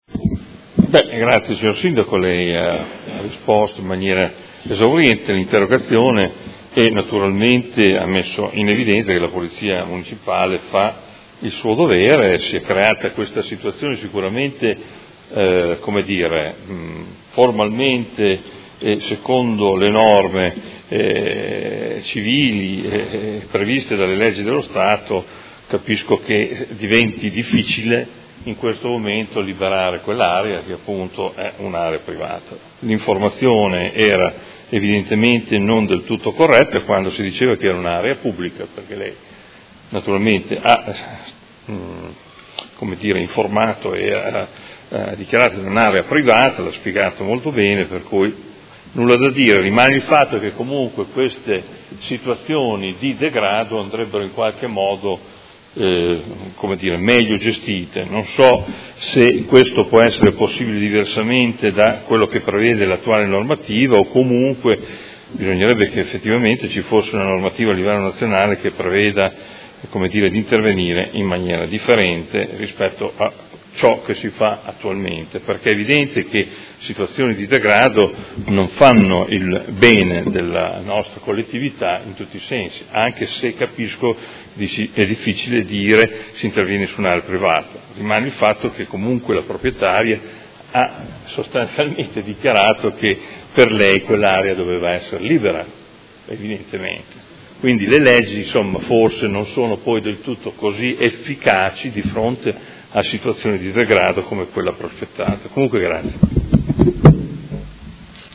Seduta del 28/06/2018. Conclude interrogazione del Consigliere Morandi (FI) avente per oggetto: Campo nomadi abusivo a Cognento